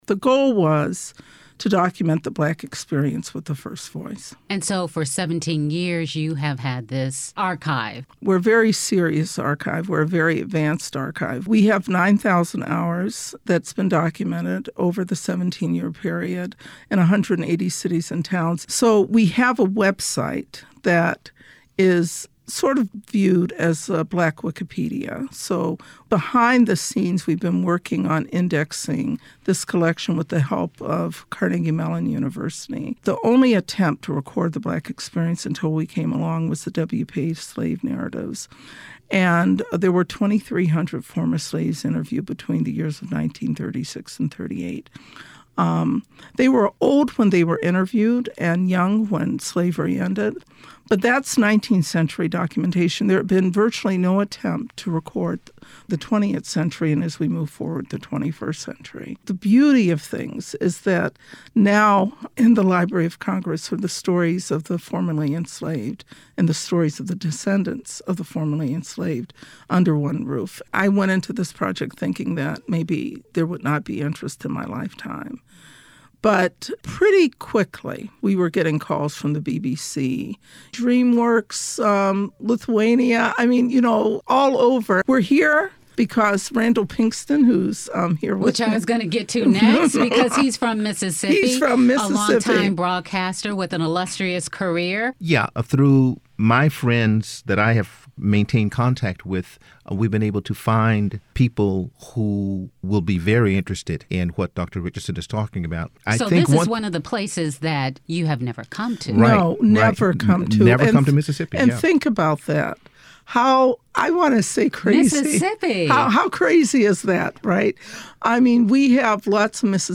Mississippi Public Broadcasting Interview
Mississippi Public Broadcasting Interview_5.25.mp3